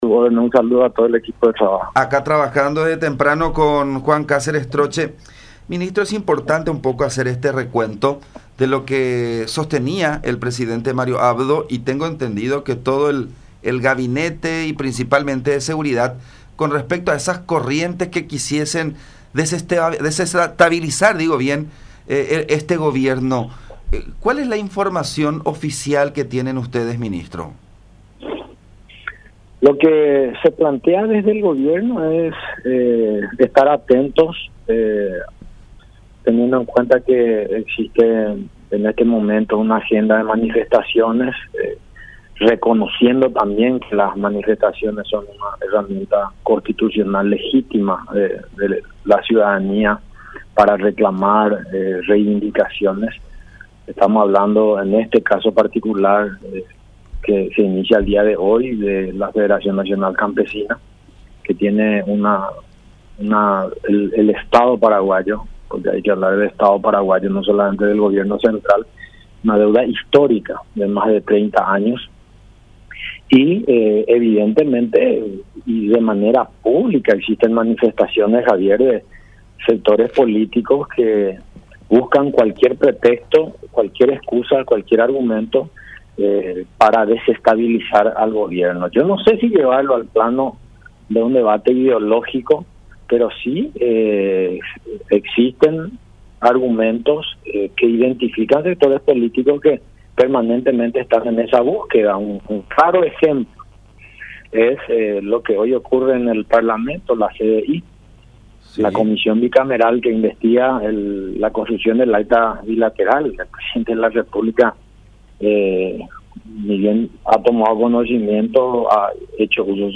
No sé si llevarlo al debate ideológico, pero sí existen argumentos que a sectores que permanentemente están en esa búsqueda”, expresó Centurión en comunicación con La Unión.